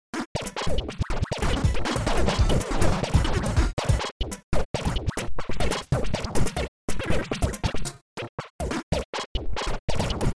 38 custom scratch sounds
38 custom scratch sounds with demo Flstudio template
38customscratchsounds.mp3